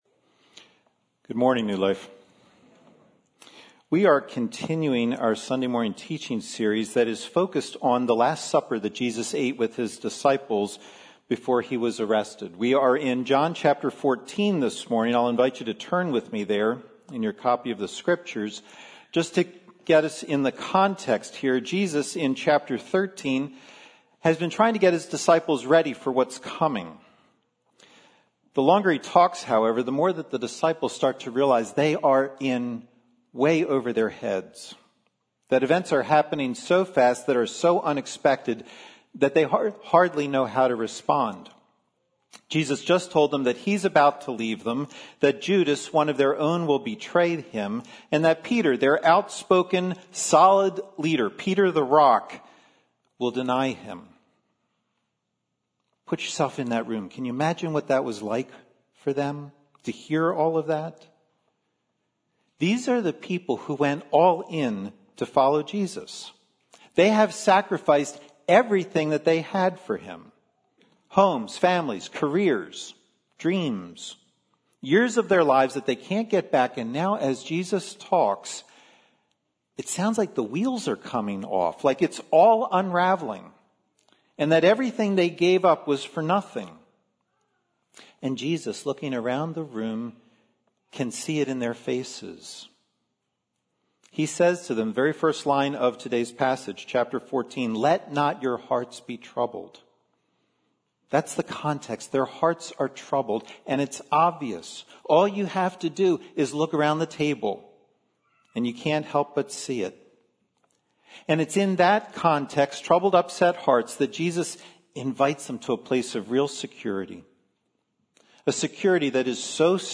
Sermons – New Life Glenside